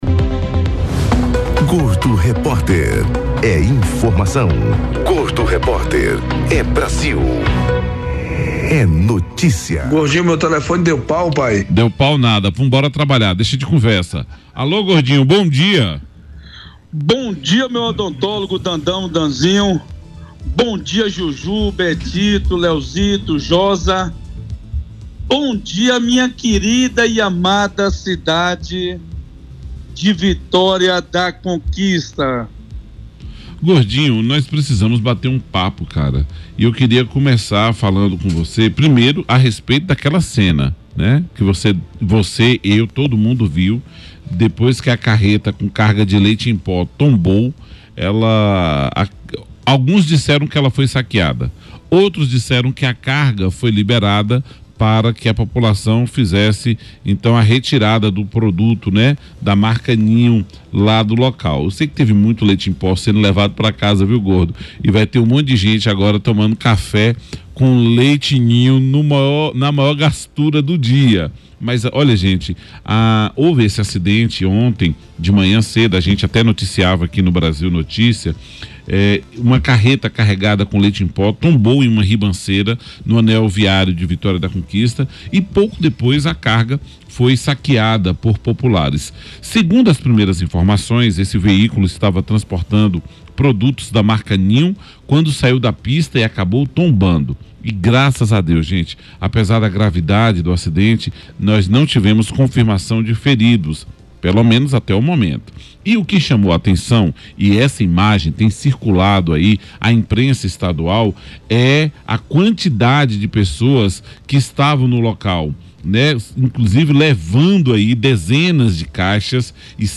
As denúncias de um suposto esquema de “rachadinha” na Câmara Municipal de Vitória da Conquista ganharam destaque na imprensa estadual e foram tema central de debate no programa Brasil Notícias, da Rádio Brasil, nesta terça-feira (14). O caso, que envolve o vereador Gilvan Nunes Pereira, o Dinho dos Campinhos, do Republicanos, teria sido formalizado junto ao Ministério Público e à Polícia Federal por um ex-assessor do parlamentar.